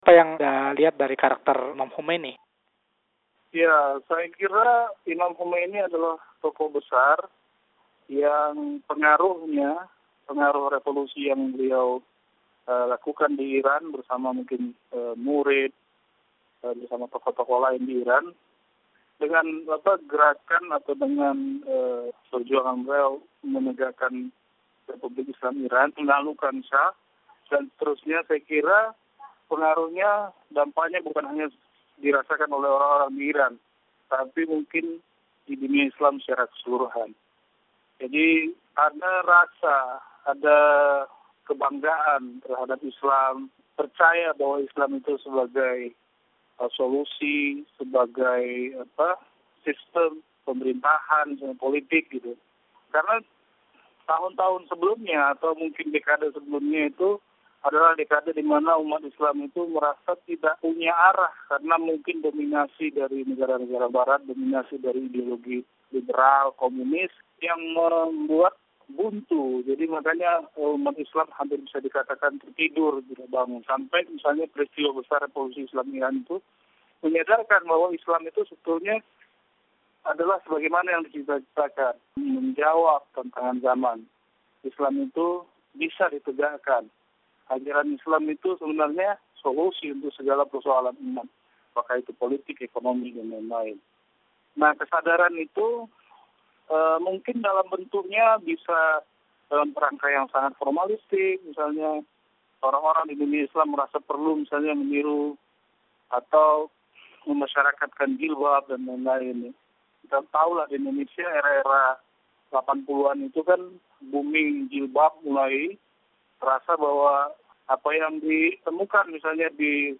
Wawancara: Imam Khomeini dari Perspektif Aktivis Indonesia